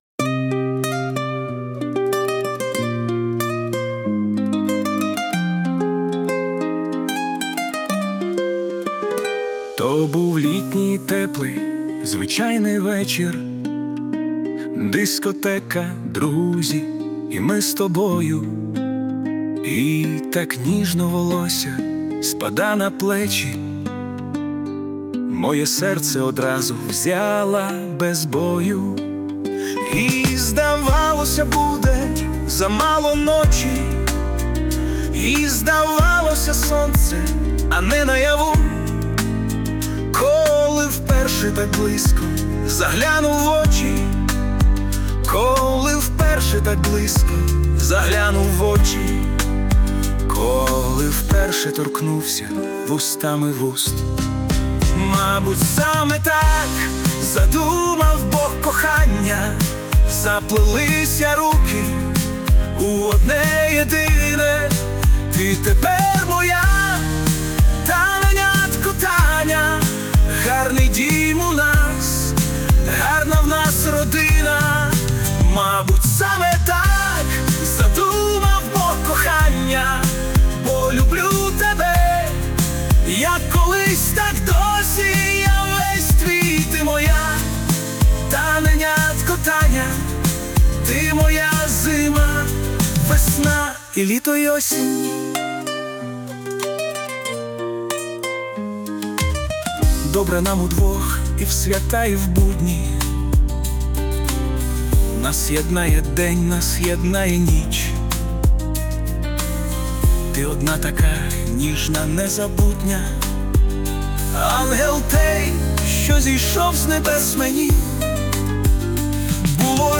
СТИЛЬОВІ ЖАНРИ: Ліричний
ВИД ТВОРУ: Авторська пісня